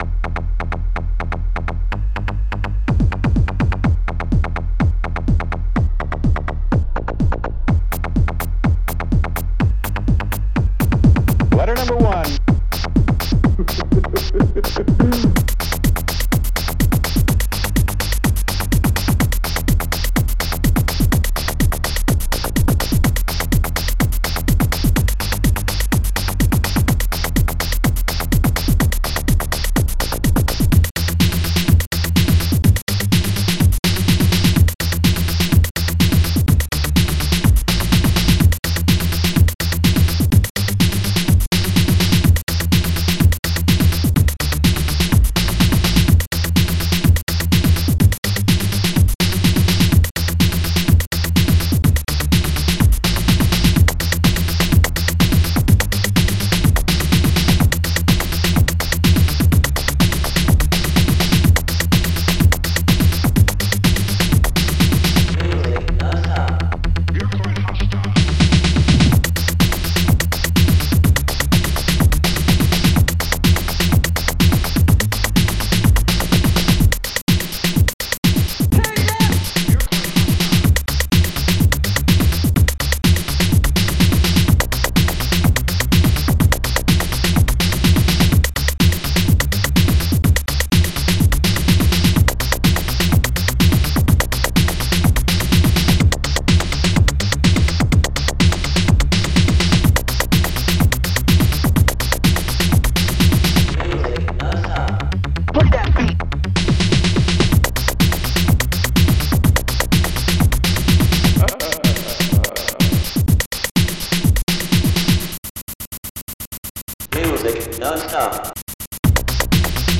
Protracker and family
ST-13:J.SNARE3
ST-55:BASS1
ST-55:WOODBLOCK